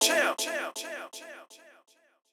{Vox} Chill Swizzy(1).wav